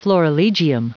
Prononciation du mot florilegium en anglais (fichier audio)
Prononciation du mot : florilegium